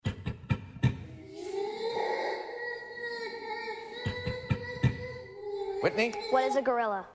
The African animal heard